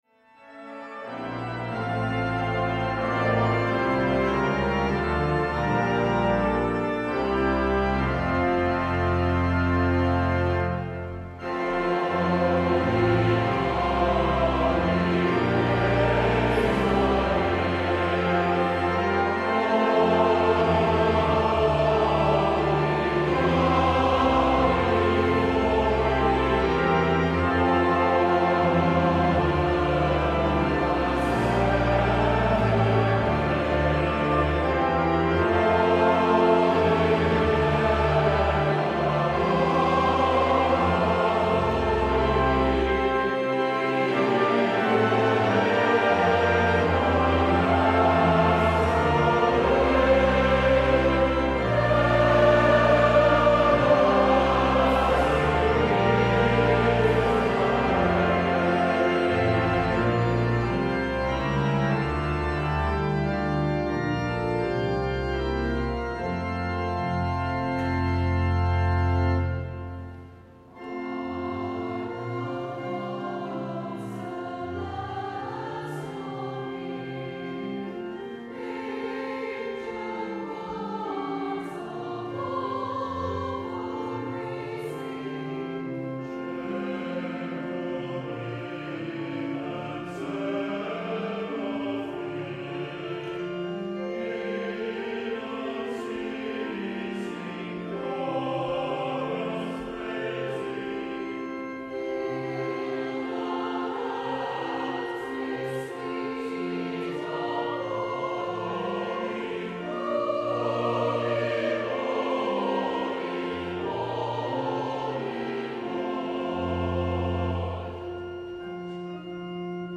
• Music Type: Choral
• Voicing: Congregation, SATB
• Accompaniment: Brass Quartet
Festive hymn-anthem